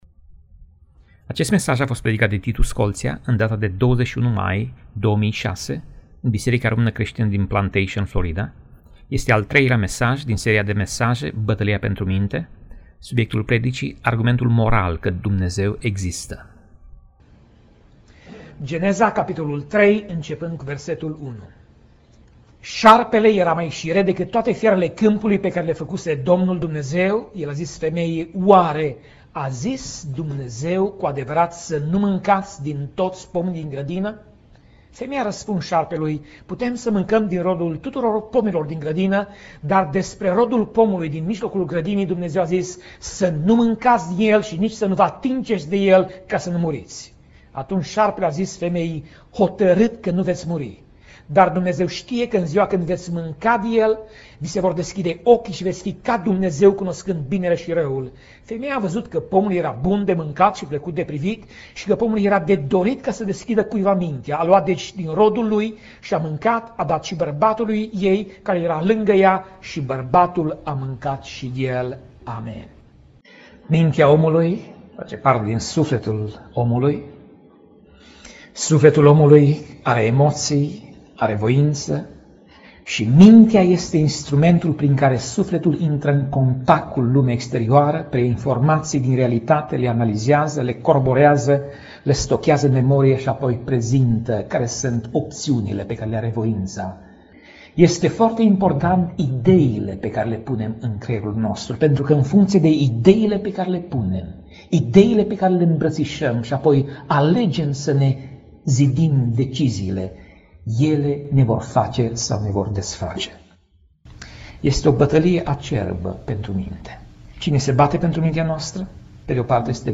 Pasaj Biblie: Romani 12:1 - Romani 2:16 Tip Mesaj: Predica